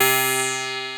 DOBLEAD C4-L.wav